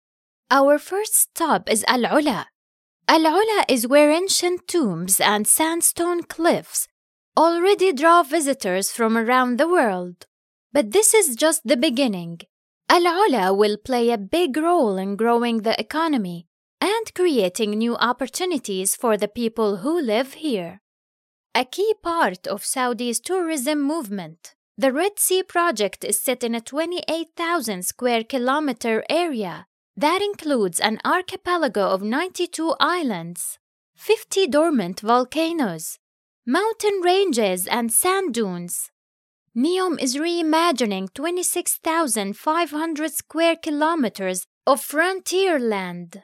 Female
Adult (30-50)
Explainer Videos
English Saudi Accent
All our voice actors have professional broadcast quality recording studios.